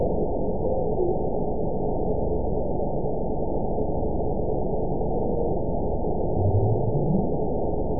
event 922299 date 12/29/24 time 04:29:43 GMT (5 months, 3 weeks ago) score 9.17 location TSS-AB02 detected by nrw target species NRW annotations +NRW Spectrogram: Frequency (kHz) vs. Time (s) audio not available .wav